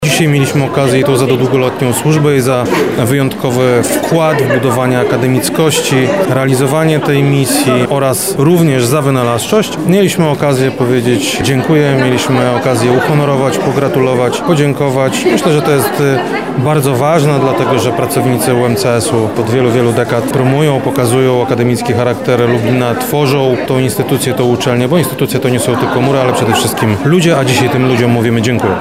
W piątek (28 listopada) w gmachu Wydziału Prawa i Administracji UMCS odbyła się uroczystość, która zgromadziła społeczność akademicką, przedstawicieli władz oraz bohaterów dnia- wyróżnionych wykładowców i pracowników.
Ta wielość odznaczeń pokazuje przede wszystkim wieloletni charakter akademickości naszego miasta – mówi Krzysztof Komorowski, wojewoda lubelski.